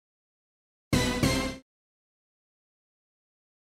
デデン！みたいな煌びやかな音！正体はオーケストラヒット！
煌びやかでかっこいいですよねぇー。
オーケストラヒットっていう音なんですねぇー。
なんかオーケストラの色んな楽器を短く鳴らした集合体らしいですね。笑
ちなみにサンプルの音もHALion Sonicの音を使っています。